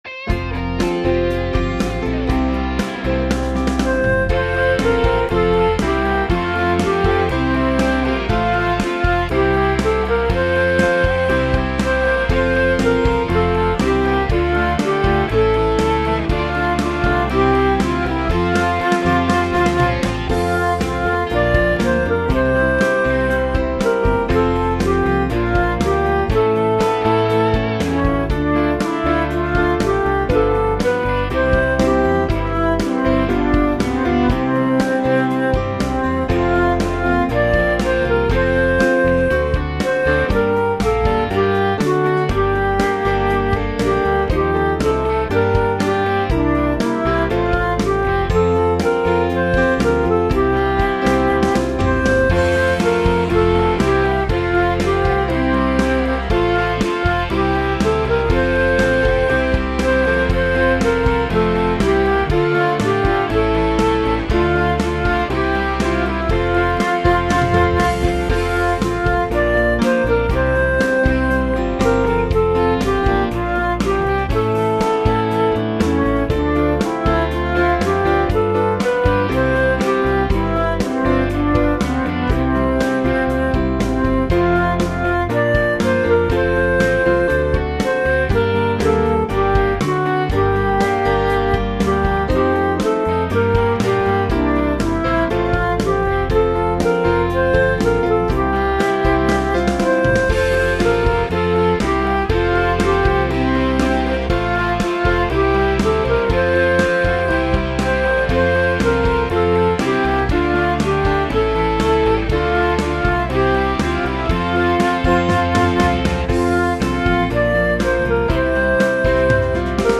My backing is less grand and more rocking: